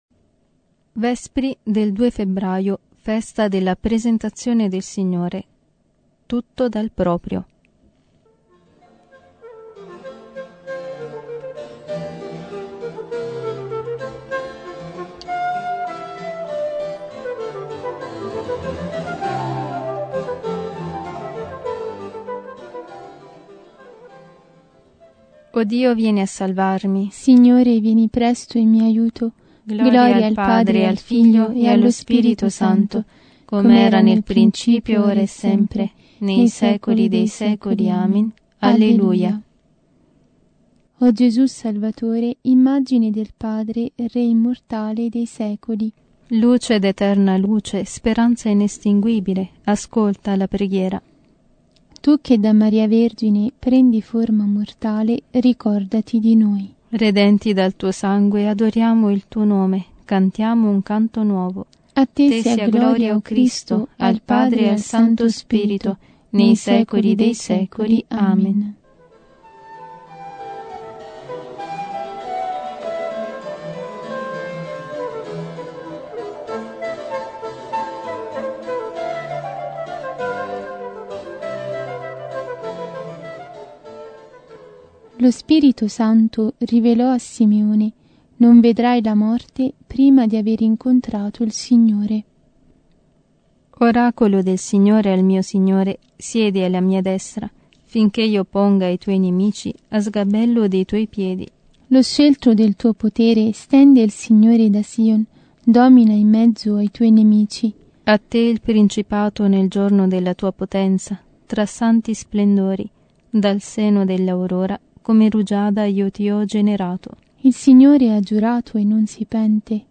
Secondi Vespri – festa della presentazione del Signore